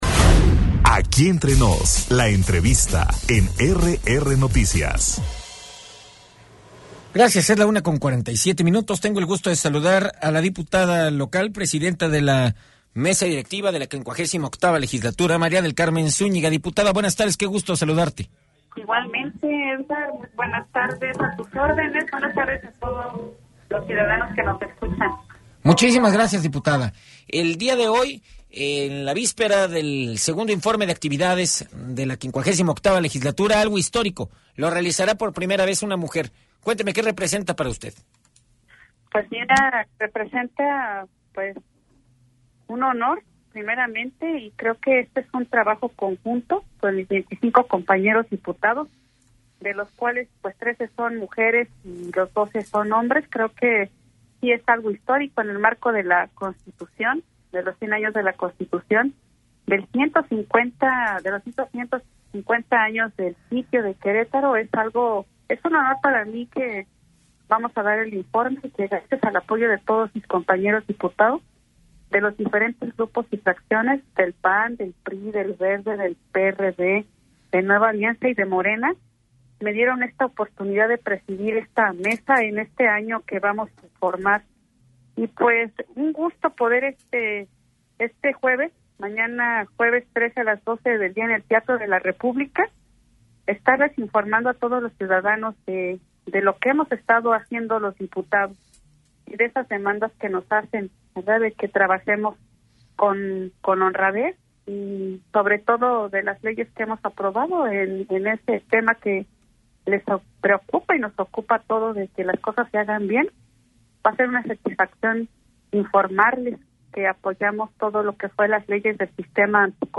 Entrevista con la presidenta de la Mesa Directiva del Congreso Local, Carmen Zuñiga, sobre informe de actividades - RR Noticias